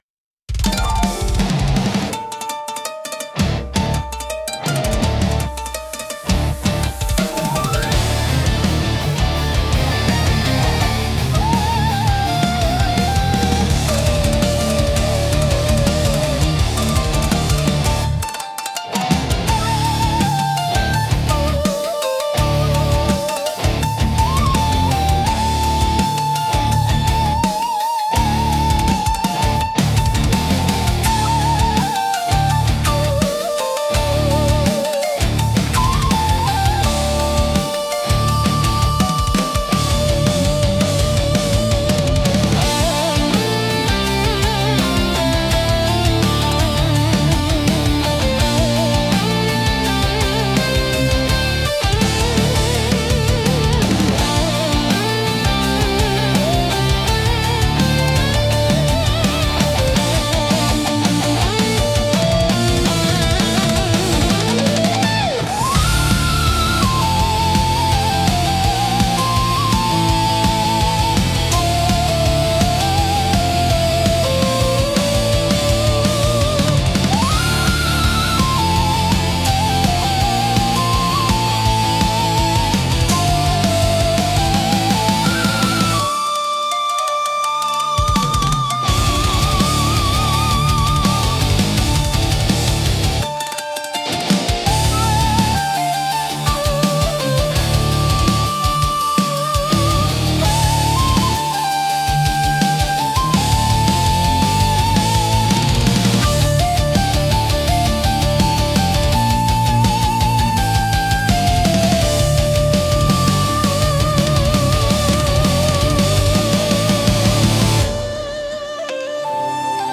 聴く人に力強さと神秘性を同時に感じさせ、日本古来の精神と現代のエネルギーを融合したインパクトを与えます。